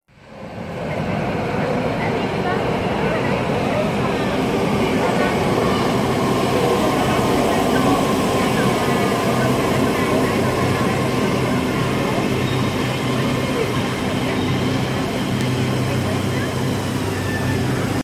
Tiếng Tàu Điện Metro chạy từ Xa tới gần…
Thể loại: Tiếng xe cộ
Description: Tiếng Tàu Điện Metro, tiếng Tàu Điện Metro Sài Gòn, Tp.HCM chạy từ Xa tới gần... từ nhỏ đến to, khi ghé bến, ghé trạm....
tieng-tau-dien-metro-chay-tu-xa-toi-gan-www_tiengdong_com.mp3